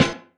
Snare (New Magic Wand).wav